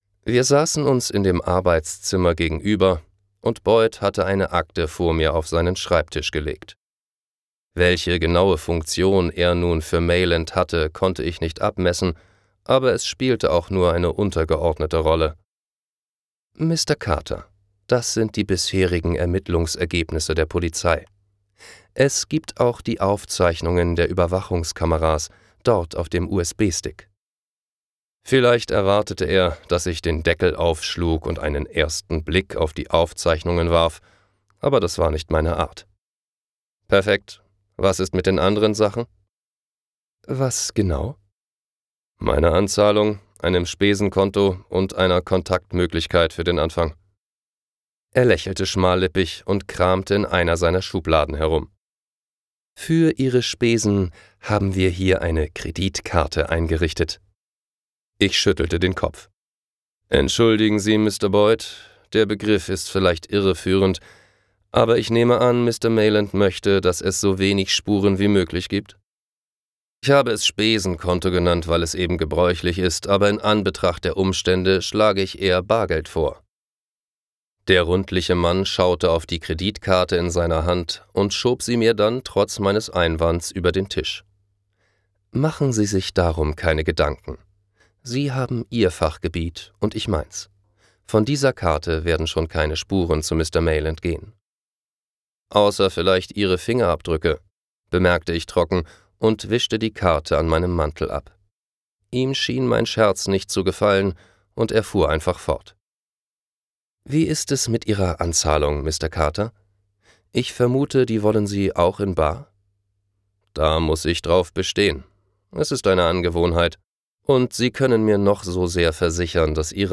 Audio/Hörbuch